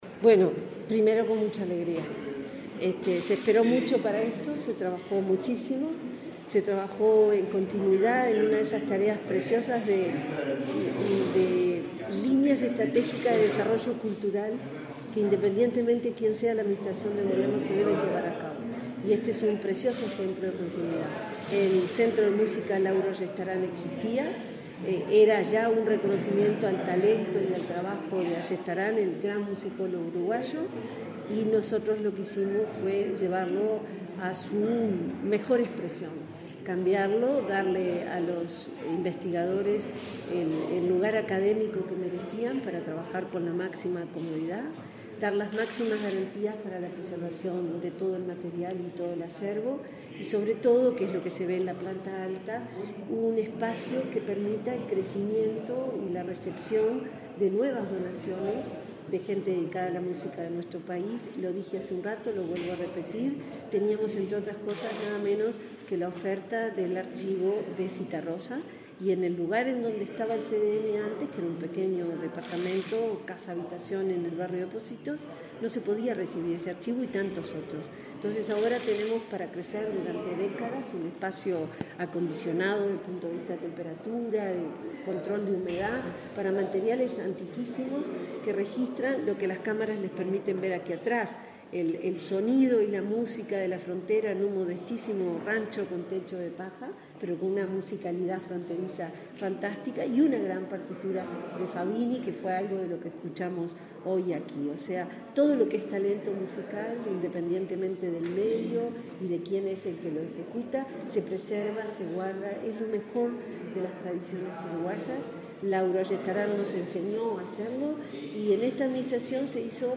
Declaraciones de la subsecretaria de Educación y Cultura
Declaraciones de la subsecretaria de Educación y Cultura 22/09/2022 Compartir Facebook X Copiar enlace WhatsApp LinkedIn La subsecretaria de Educación y Cultura, Ana Ribeiro, participó en la inauguración de la sede del Centro Nacional de Documentación Musical Lauro Ayestarán, en la Biblioteca Nacional.